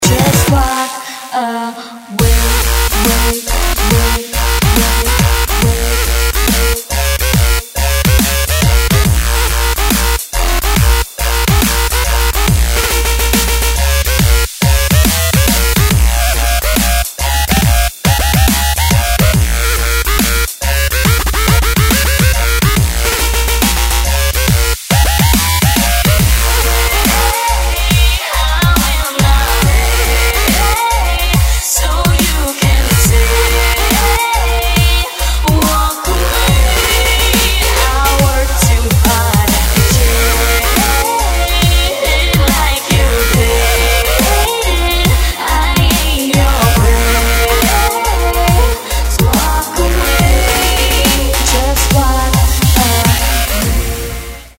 • Качество: 128, Stereo
легкий дабстеп